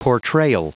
Prononciation du mot portrayal en anglais (fichier audio)
Prononciation du mot : portrayal